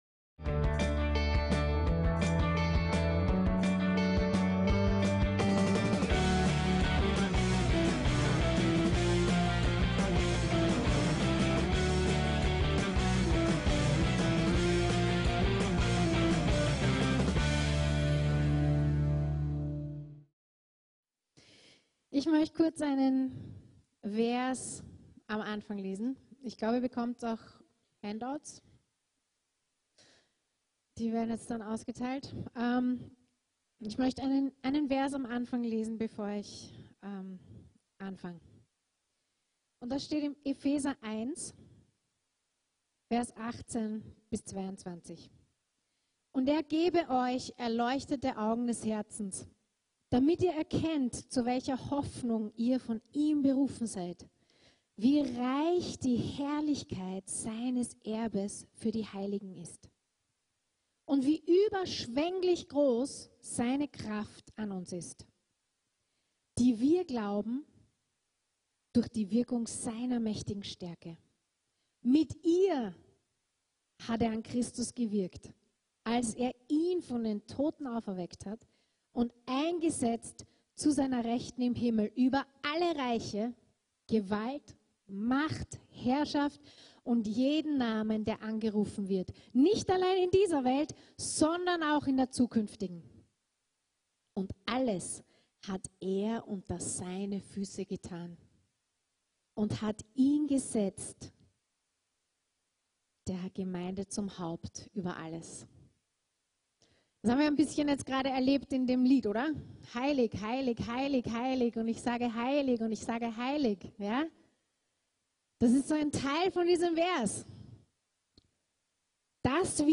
ÜBERNATÜRLICHES LEBEN ~ VCC JesusZentrum Gottesdienste (audio) Podcast